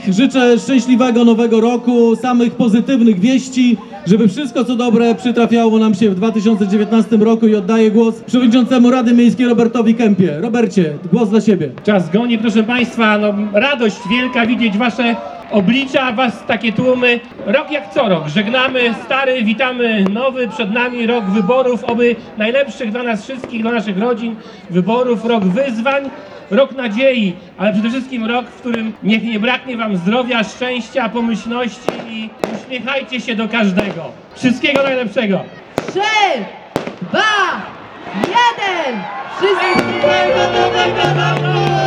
Przed północą życzenia na Nowy Rok złożył mieszkańcom Wojciech Iwaszkiewicz, burmistrz Giżycka oraz przewodniczący Rady Miejskiej Robert Kempa.